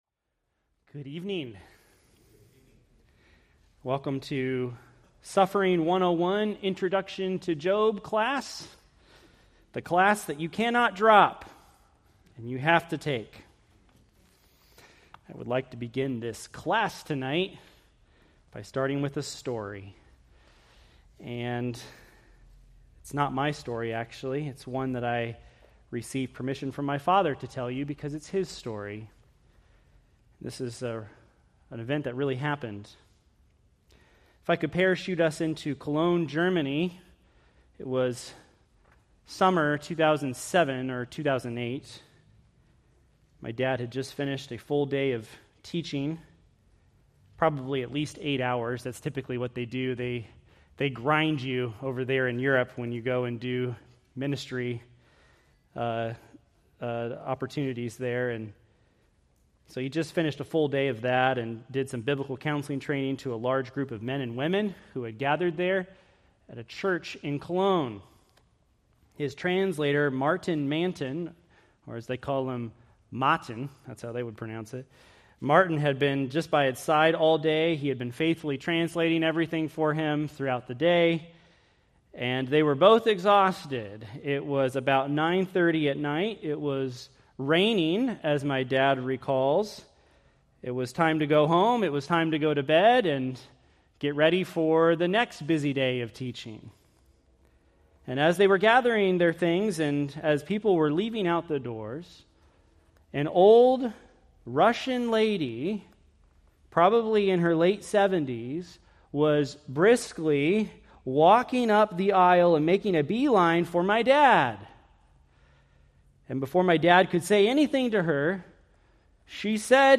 Preached March 8, 2026 from Book of Job